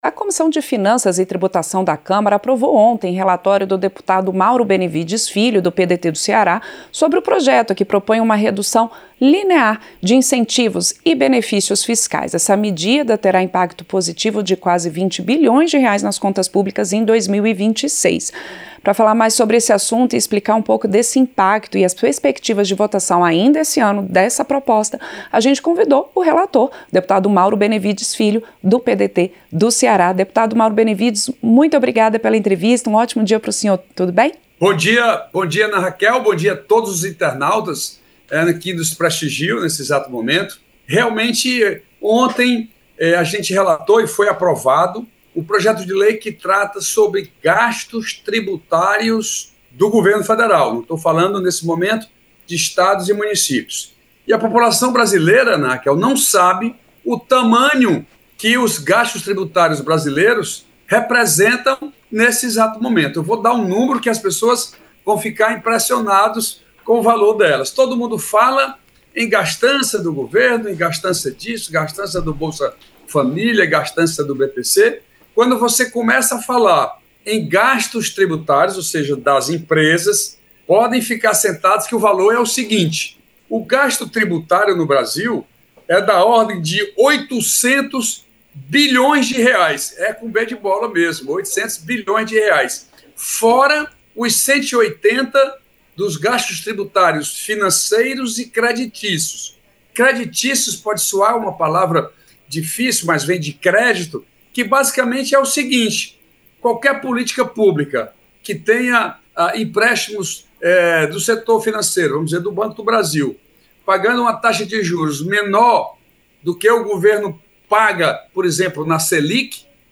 Entrevista - Dep. Mauro Benevides Filho (PDT-CE)